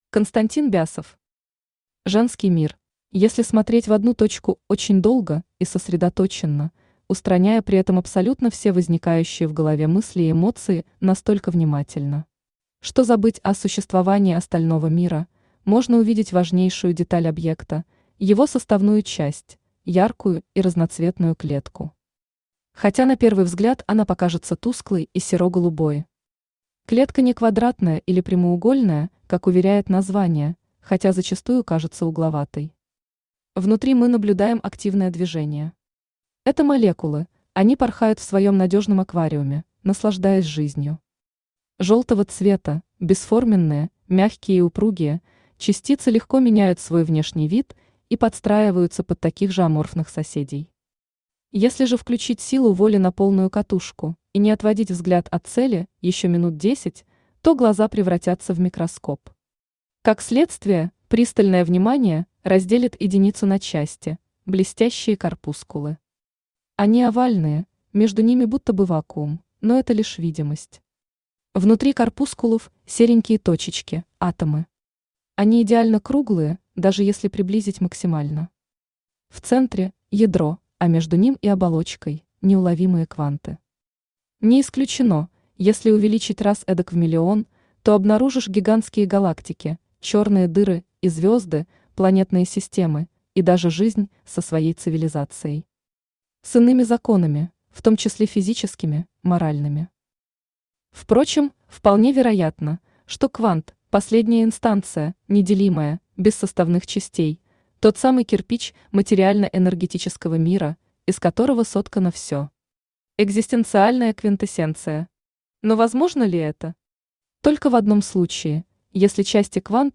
Аудиокнига Женский мир | Библиотека аудиокниг
Aудиокнига Женский мир Автор Константин Бясов Читает аудиокнигу Авточтец ЛитРес.